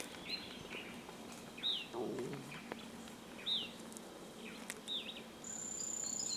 Mosqueta Parda (Lathrotriccus euleri)
Nombre en inglés: Euler´s Flycatcher
Fase de la vida: Adulto
Localidad o área protegida: Parque Provincial Urugua-í
Condición: Silvestre
Certeza: Vocalización Grabada
mosqueta-parda.mp3